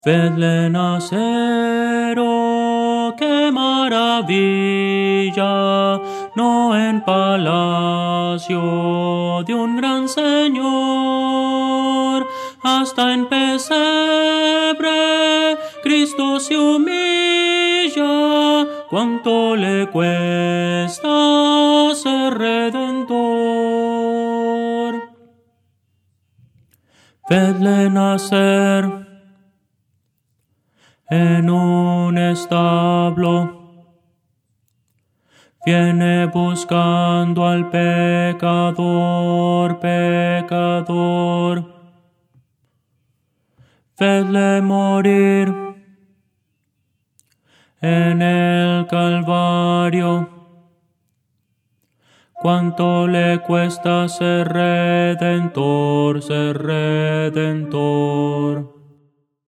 Voces para coro
Soprano – Descargar
Audio: MIDI